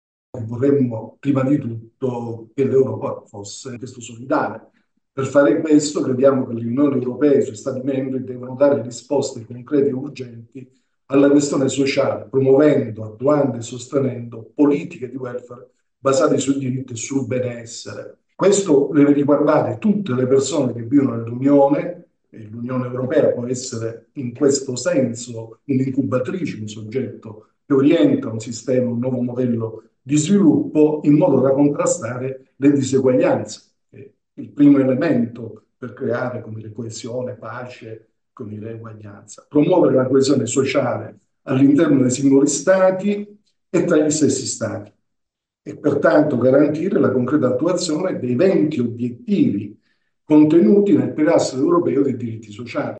I diritti devono tornare centrali, come è emerso nel corso della presentazione del Manifesto del Forum per i candidati dello scorso 23 maggio.
Un estratto del suo intervento.